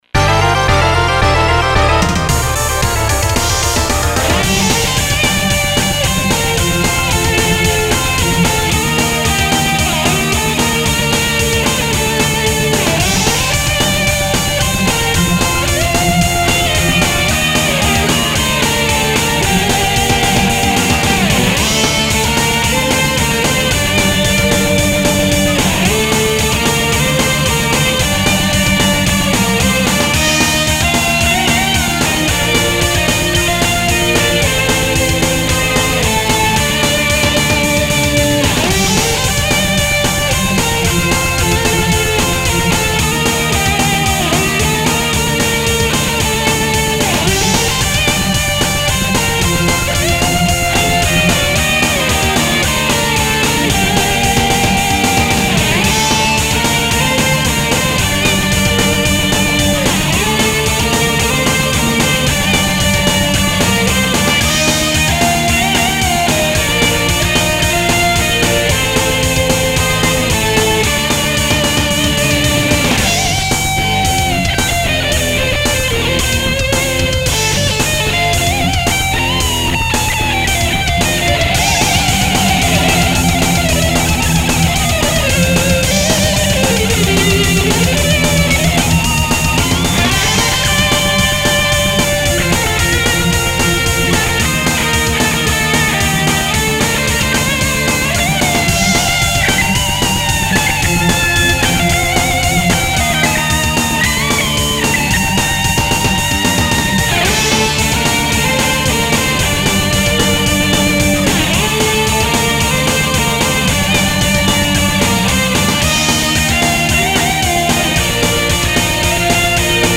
ゲーム音楽アレンジ集
ネオクラっぽくしたかったが技量が無いので中途半端になってしまった。